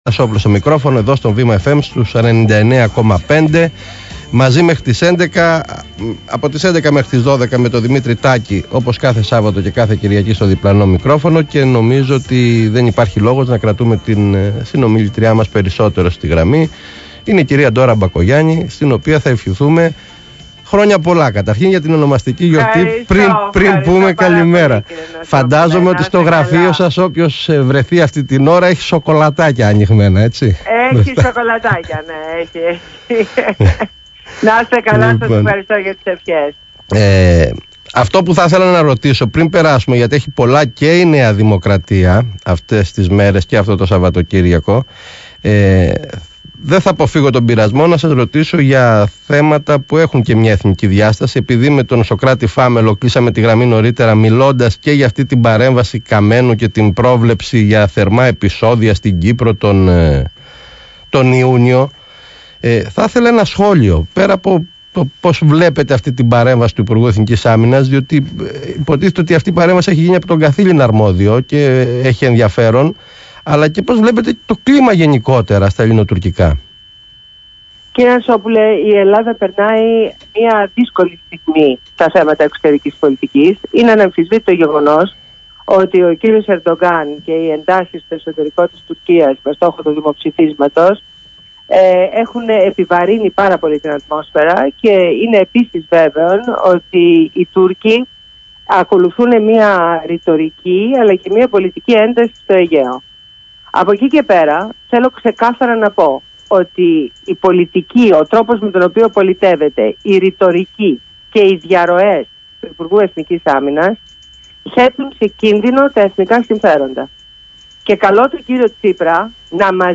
Σημεία συνέντευξης στο ΒΗΜΑ FM